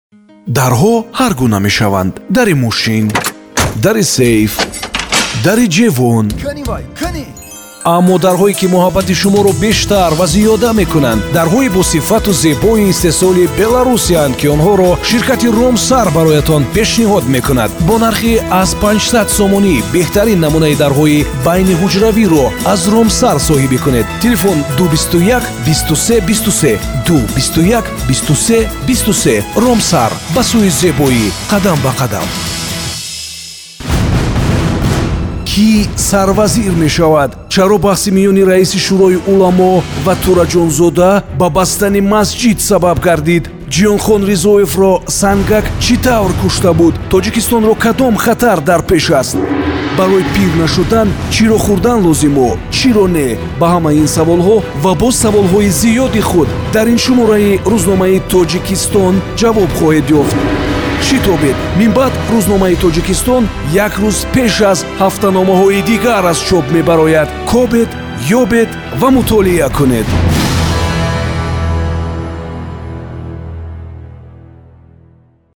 Tacikçe Seslendirme
Erkek Ses